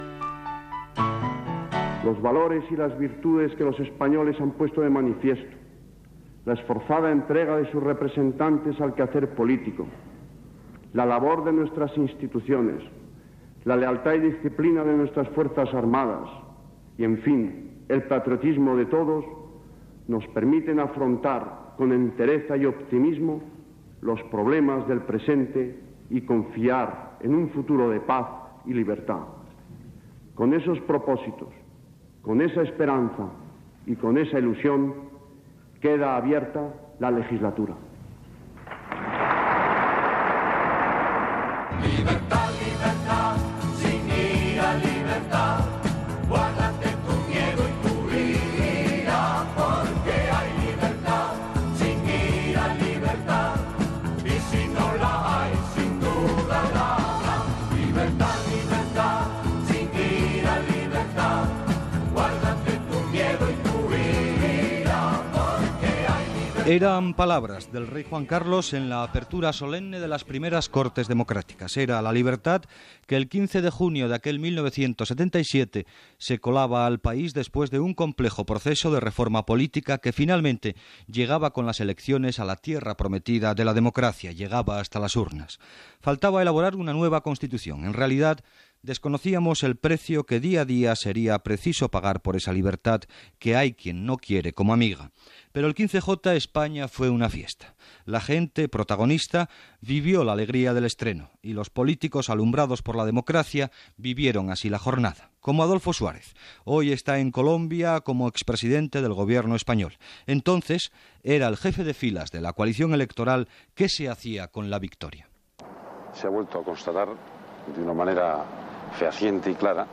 Quatre anys des de les primeres eleccions democràtiques espanyoles després del franquisme. Amb declaracions d'Adolfo Suárez, Felipe González, record del resultat de les eleccions espanyoles de 1977 i declaració de Santiago Carrillo.
Informatiu